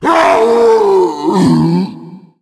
Tank_Death_06.wav